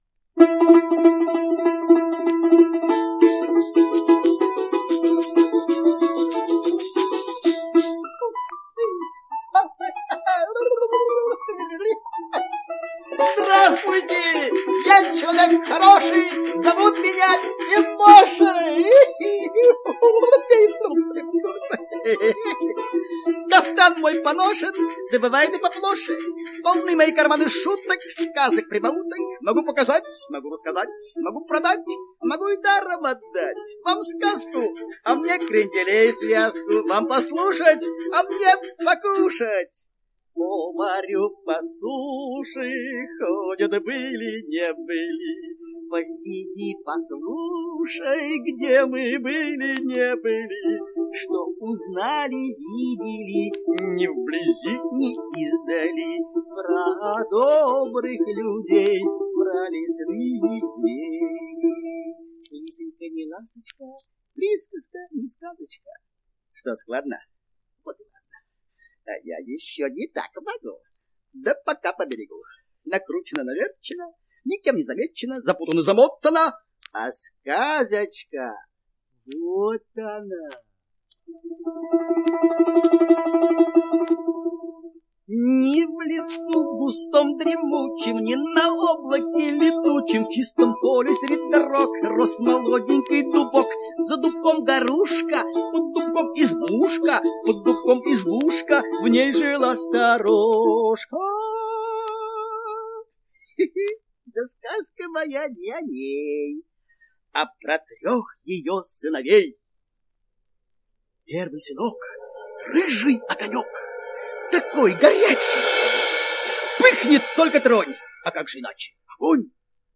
Дождь, Огонь и Ветер - аудиосказка Яхнина - слушать онлайн